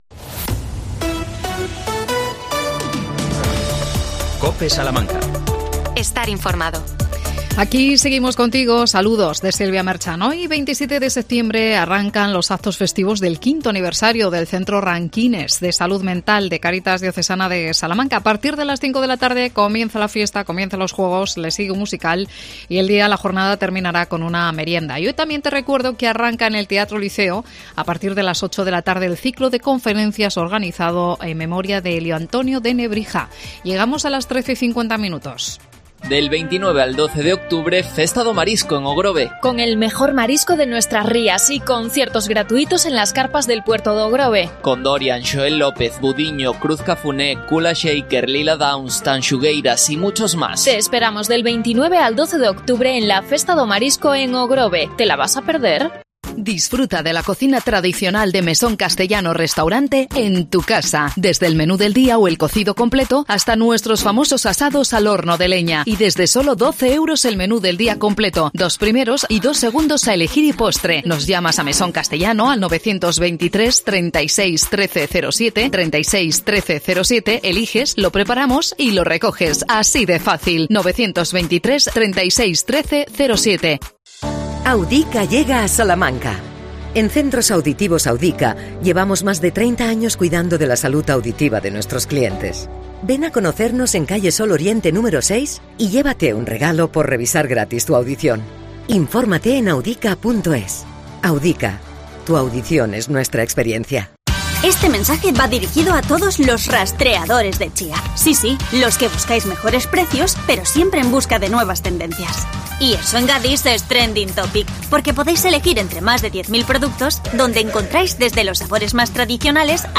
AUDIO: 40 aniversario de ASPAS SALAMANCA. Entrevistamos